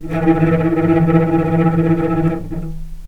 vc_trm-E3-pp.aif